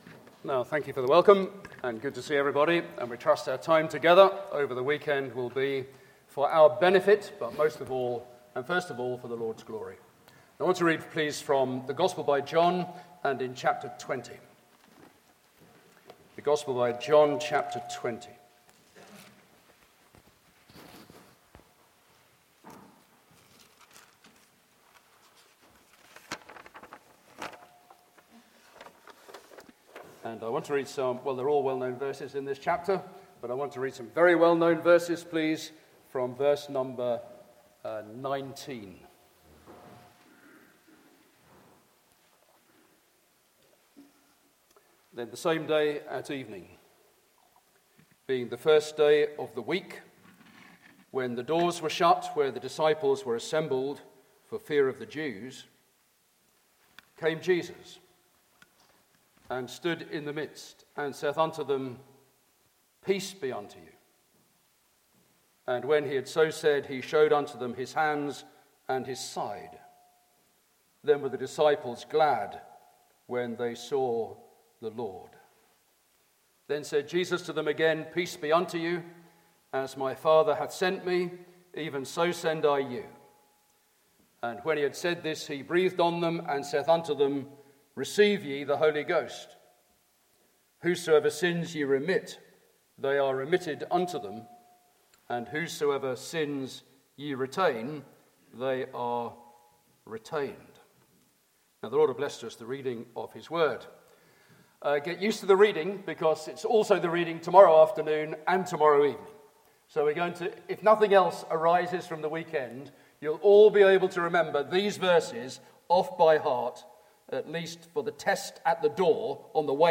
Meeting Type: Ministry